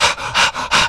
MALE PANT.wav